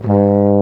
Index of /90_sSampleCDs/Roland LCDP12 Solo Brass/BRS_Baritone Hrn/BRS_Euphonium
BRS BARI G#1.wav